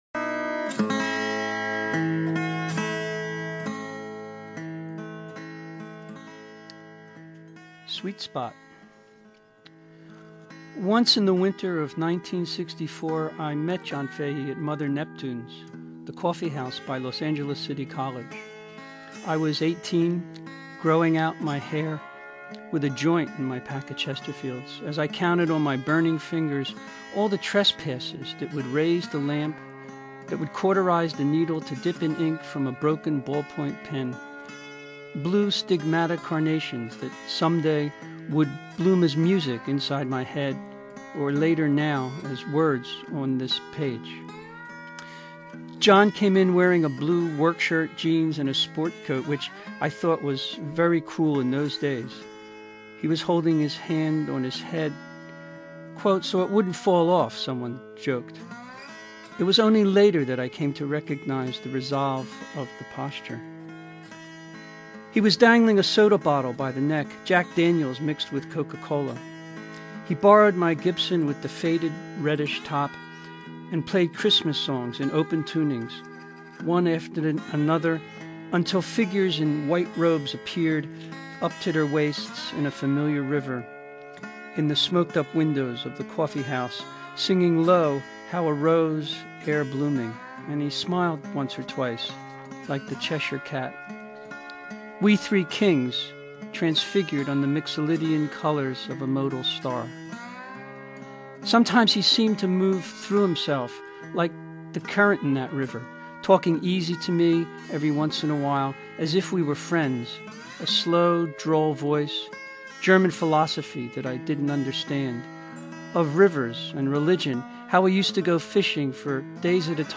Live Readings